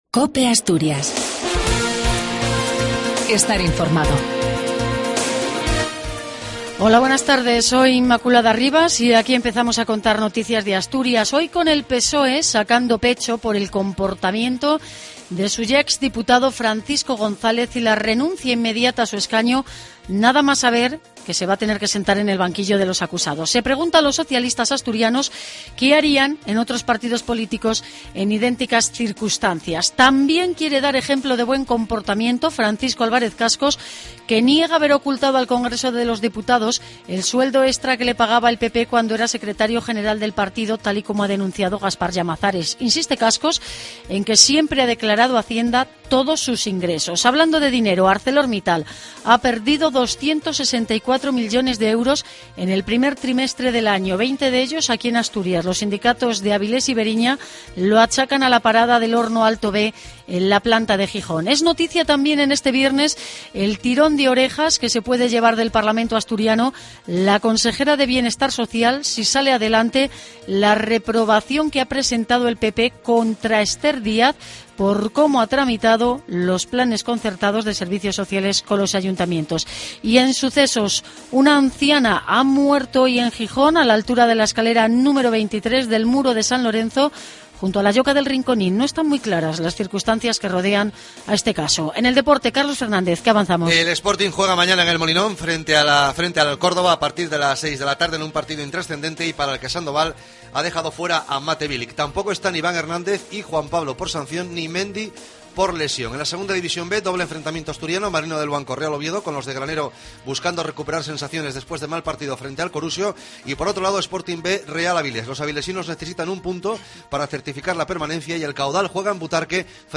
AUDIO: LAS NOTICIAS DE ASTURIAS AL MEDIODIA.